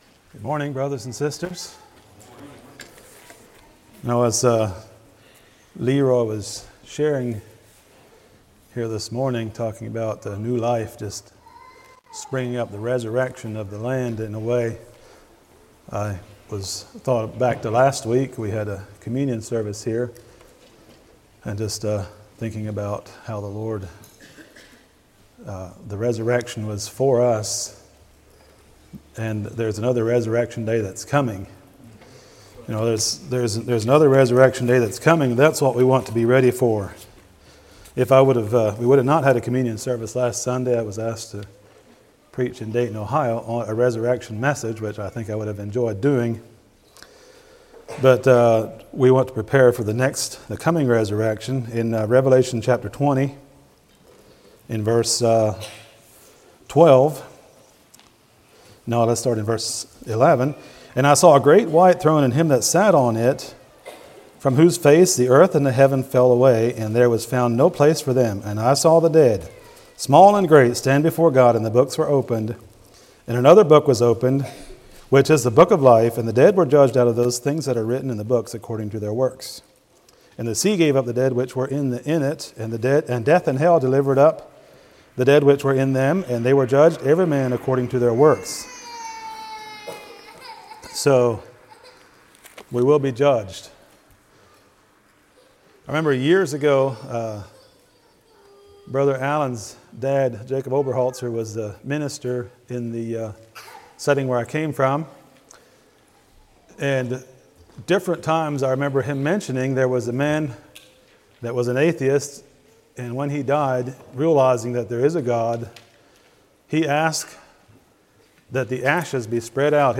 Sermons - Blessed Hope Christian Fellowship